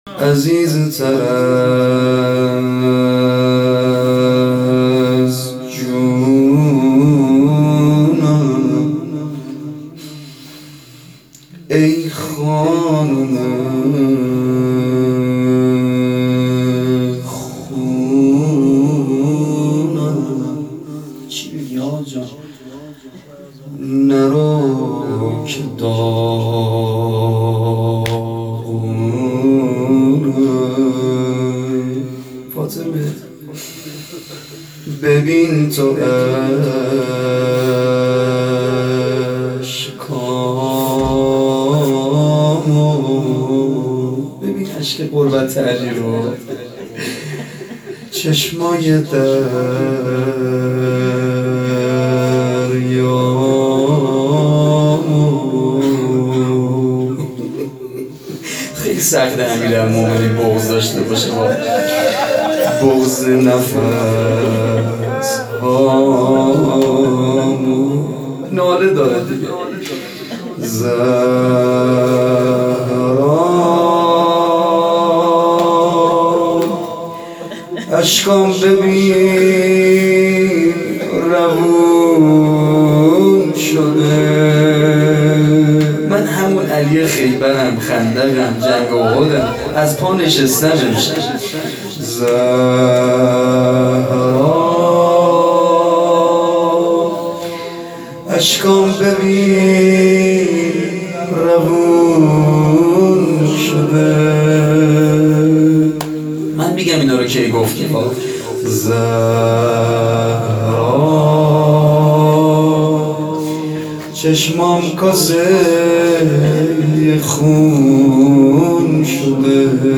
شام_شهادت حضرت زهرا سلام الله علیه_فاطمیه اول۹۷